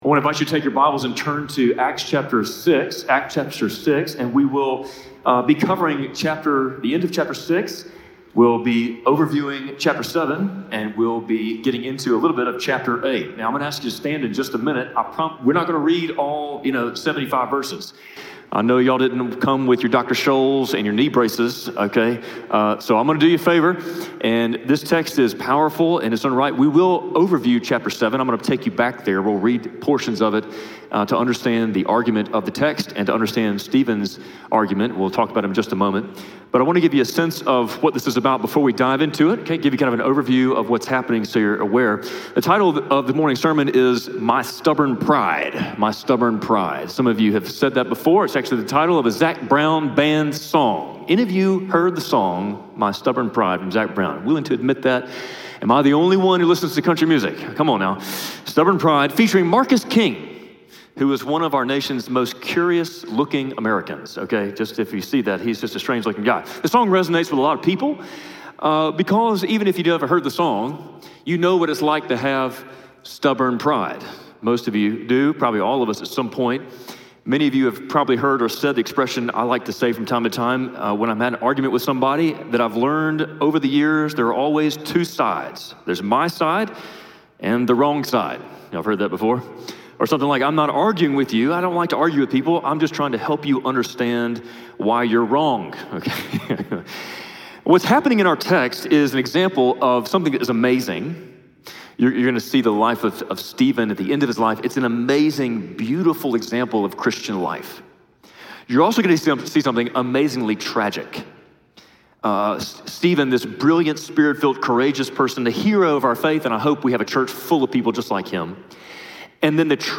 Spring Hill Baptist Sunday Sermons (Audio) / My Stubborn Pride